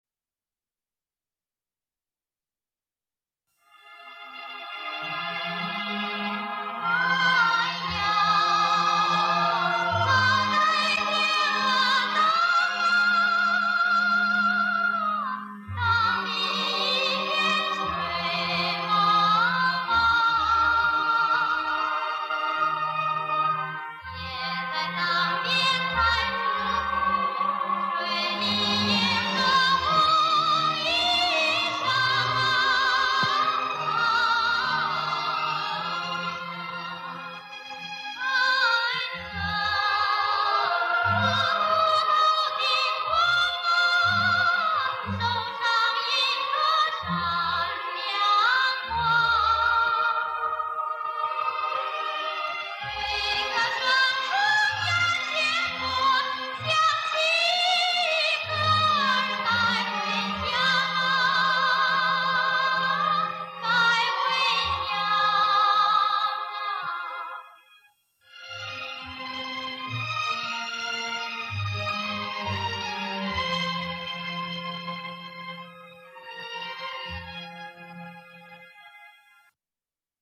（同名电影插曲）